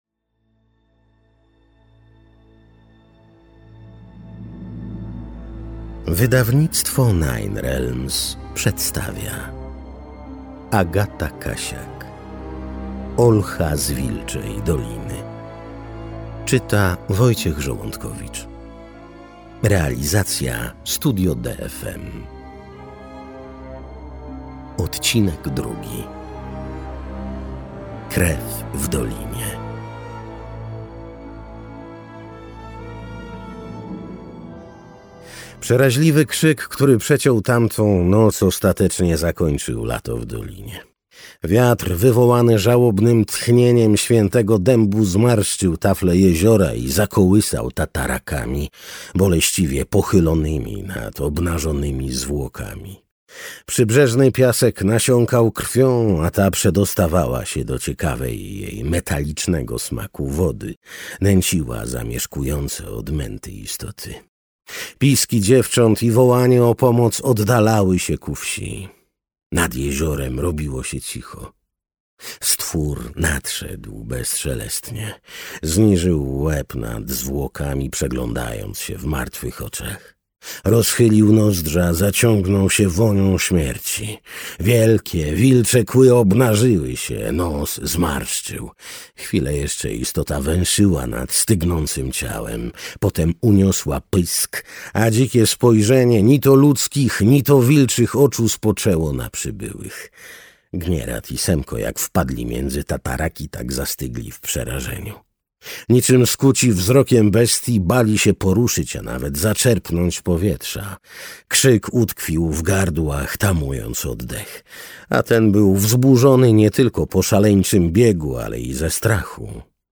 Audiobook Olcha z Wilczej Doliny.